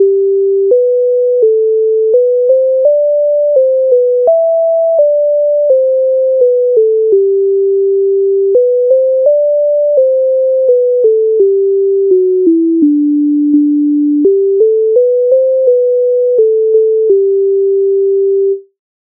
MIDI файл завантажено в тональності G-dur
А в тому саду Українська народна пісня з обробок Леонтовича с. 102
Ukrainska_narodna_pisnia_A_v_tomu_sadu.mp3